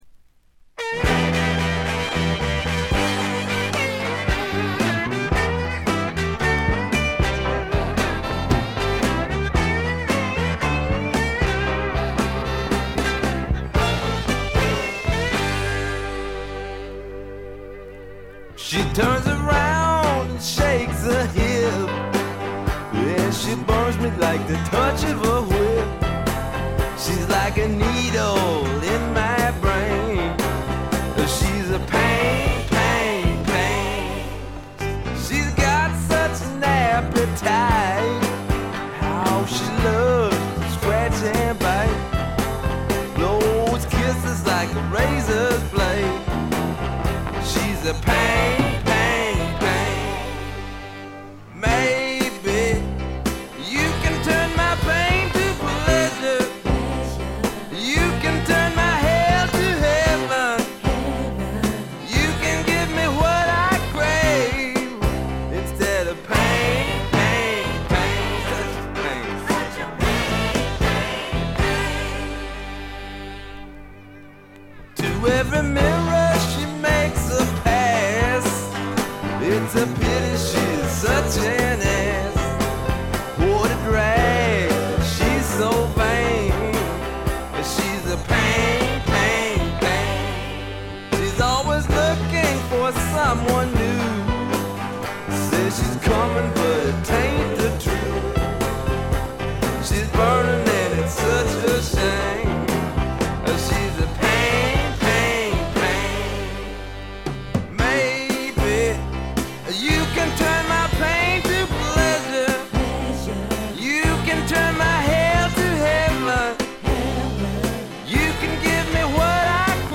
ほとんどノイズ感なし。
よりファンキーに、よりダーティーにきめていて文句無し！
試聴曲は現品からの取り込み音源です。